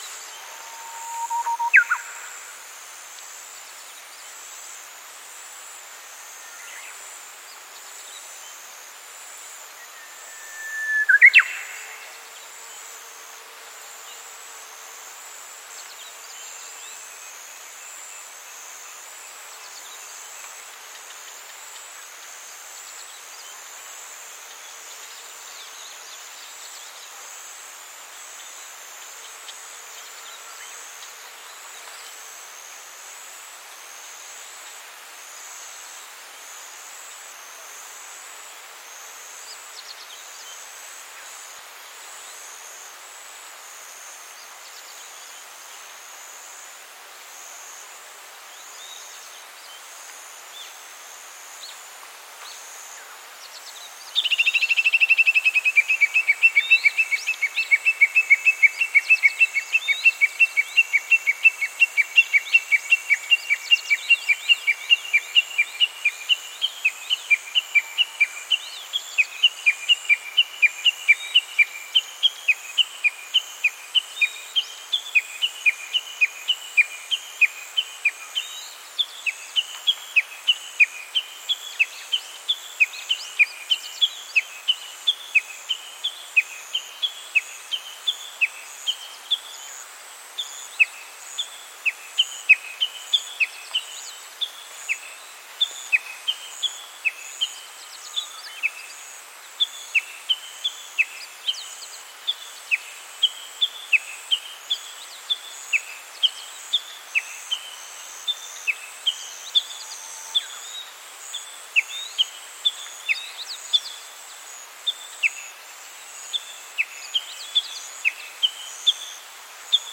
鹿角市尾去沢水晶山 12:30〜14:50
03.ウグイス [Cettia diphone]
soundウグイス (mp3 2分58秒)13時00分過頃収録 尾去沢水晶山